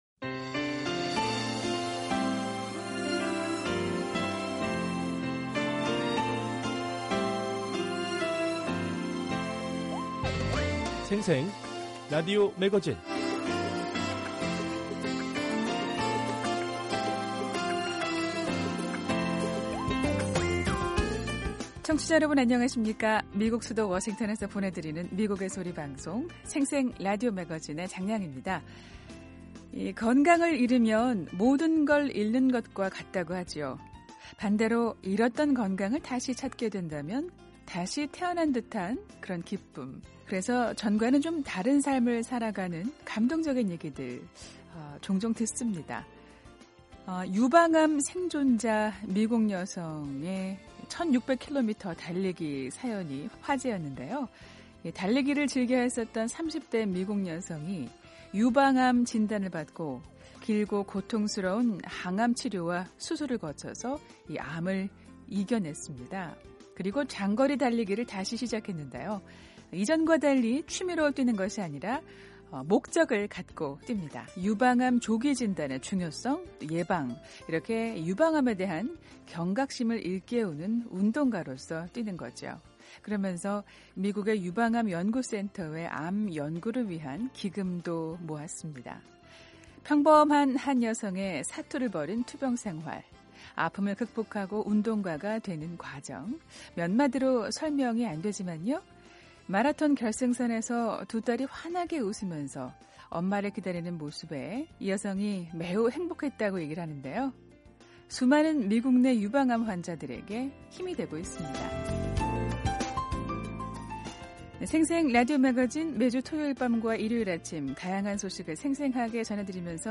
유엔 제3위원회 상호대화회의에서 북한 토마스 오헤아 퀜타나 인권 특별 보고관이 북한의 핵 미사일 개발로 인한 동북아 긴장상태가 북한의 인권과 민생 개선에 영향을 미친다고 발표했습니다. 이 내용으로 취재 기자와 이야기 나눕니다. 한국 내 비영리 북한인권단체 통일맘 연합회가 미국에서 중국 내 탈북여성의 인권상황과 이들의 중국 내 자녀들과의 권리를 보장받기 위해 운동을 벌이고 있습니다.